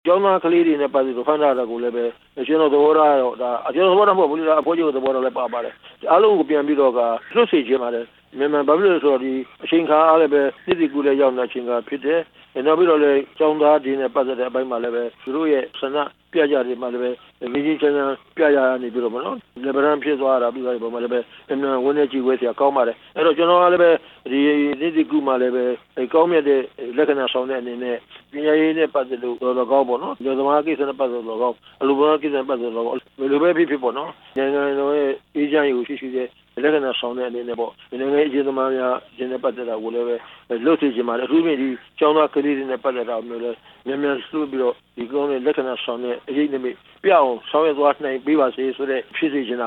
NLD သဘာပတိအဖွဲ့ ဥက္ကဌ ဦးတင်ဦးကလည်း အခုလိုနှစ်ကူးသမယမှာ အကြမ်းဖက်ဖမ်းဆီးခံနေရသူအားလုံး လွတ်မြောက်စေချင်ပါတယ်လို့ RFA ကို ပြောကြားလိုက်ပါတယ်။